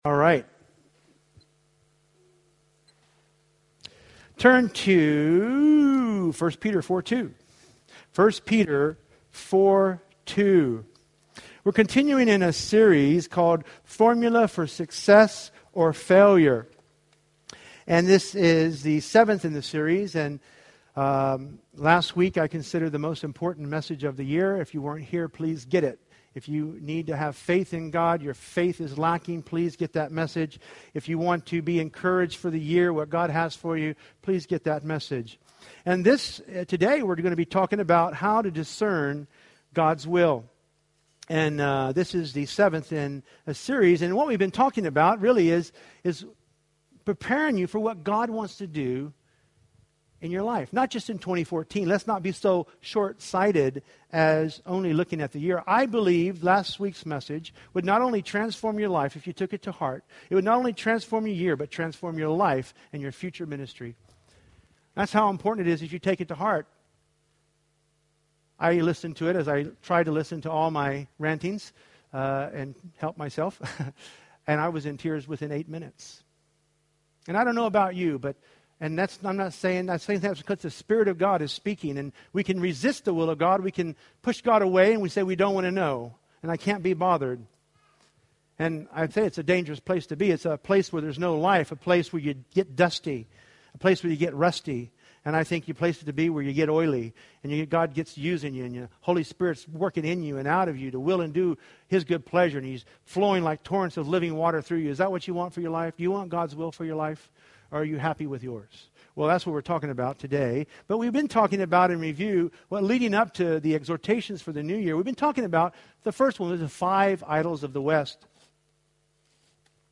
1 Peter 4:2 Preached in 2013 at Pillar Community Church, Swansea, Wales, UK